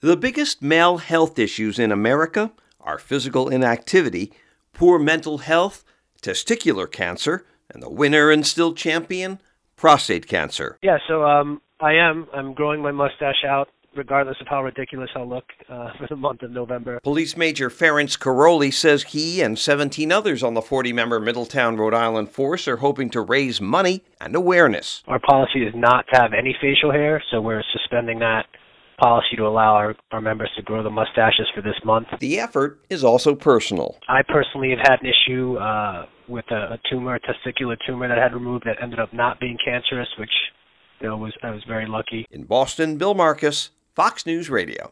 (BOSTON) OCT 30 – A FOUNDATION CALLED MOVEMBER – WITH AN “M”  – HAS BEEN RAISING MONEY, AWARENESS AND WHISKERS FOR MEN’S HEALTH EACH NOVEMBER SINCE THE MOVEMENT STARTED IN AN AUSTRALIAN PUB 16 YEARS AGO.  NOW THAT EFFORT HAS TAKEN HOLD IN RHODE ISLAND AS FOX NEWS RADIO’S